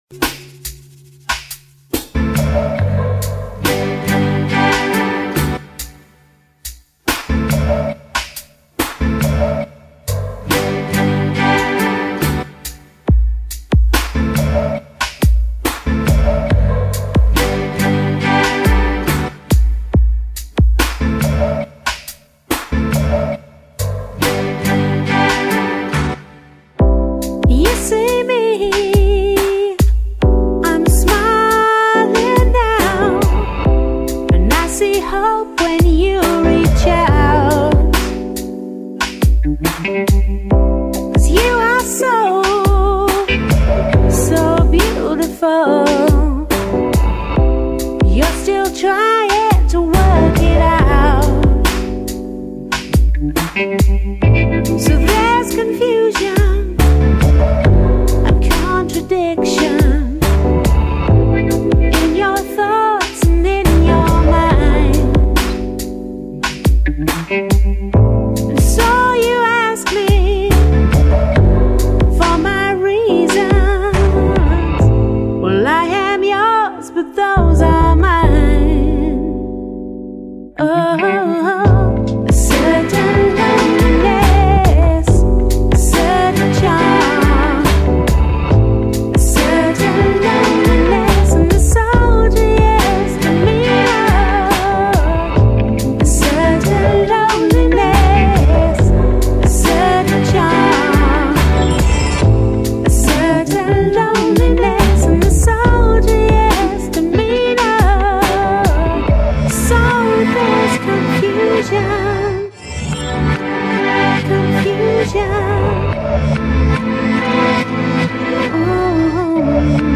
甜美中氤蕴迷幻，舒适解压的聆听旅程。
Bossa Nova，Downtempo，Nu Jazz 与 Lounge